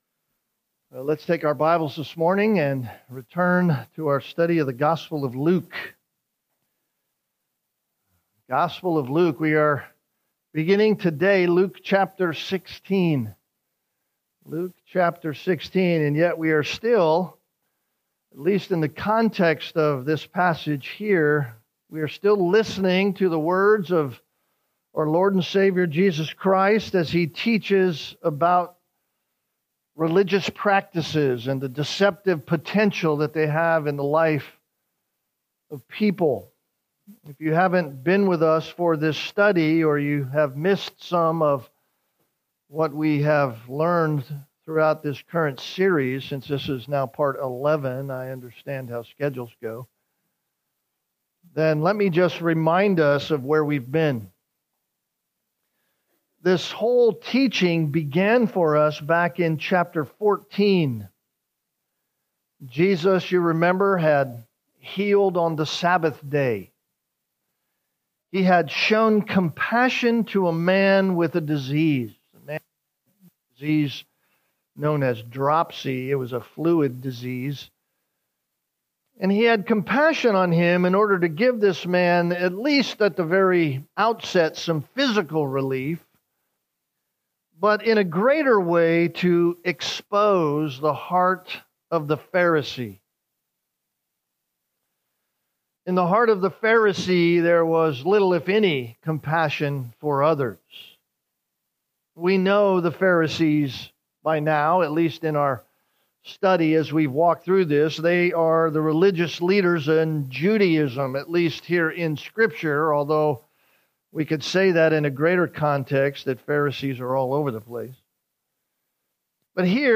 Sunday Morning - Fellowship Bible Church